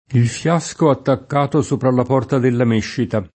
il fL#Sko attakk#to S1pra lla p0rta della m%ššita] (D’Annunzio); davanti al banco della méscita [